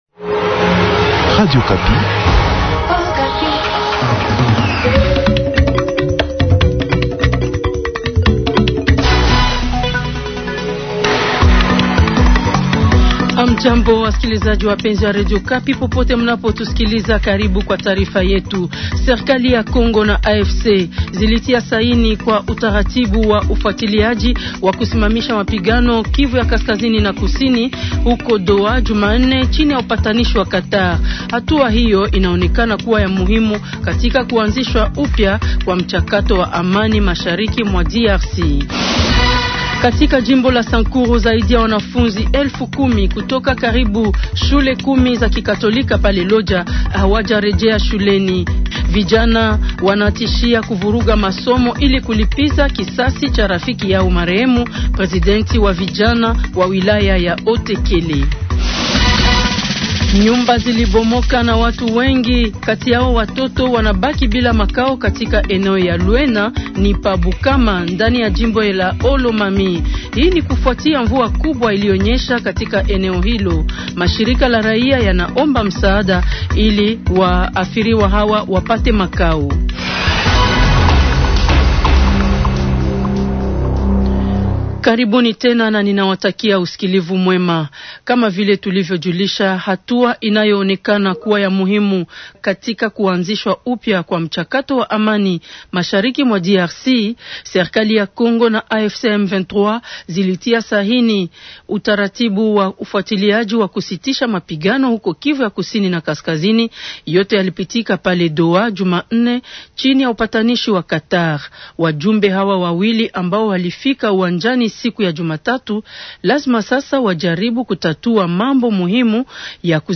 Journal swahili MATIN du 15 octobre 2025.